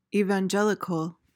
PRONUNCIATION:
(ee-van-JEL-i-kuhl)